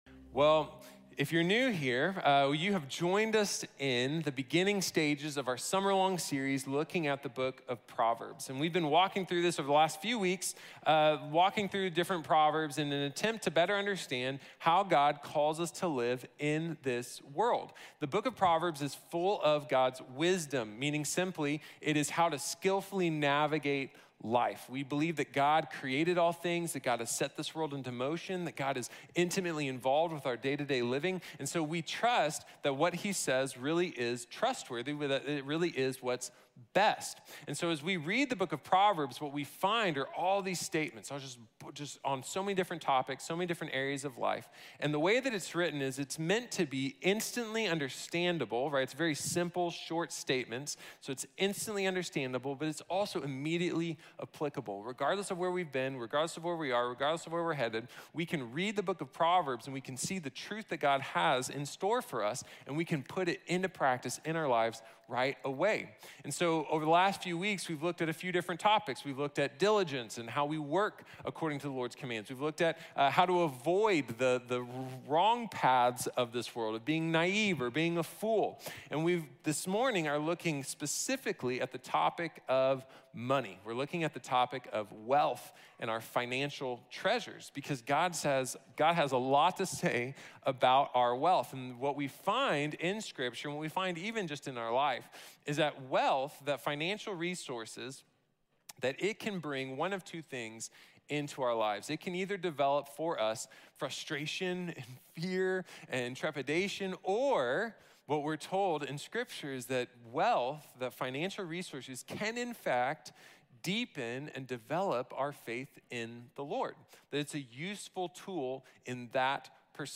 Wise Wealth | Sermon | Grace Bible Church